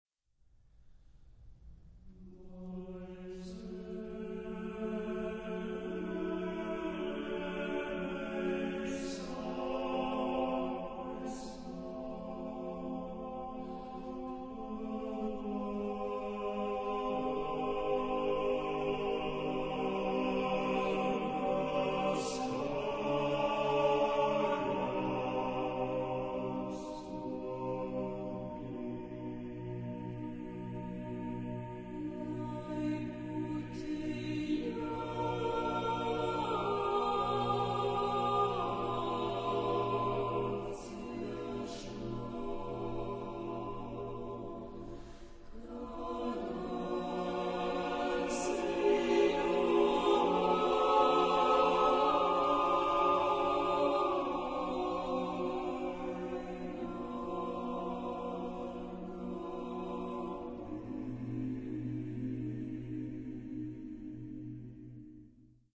Genre-Stil-Form: weltlich ; zeitgenössisch
Charakter des Stückes: nachdenklich ; fromm ; fliessend
Chorgattung: SATB (div.)  (8 gemischter Chor Stimmen )
Tonart(en): chromatisch ; polytonal